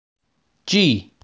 Alfabeto em Inglês Pronúncia a letra G
Alfabeto-em-Inglês-Pronúncia-a-letra-G.wav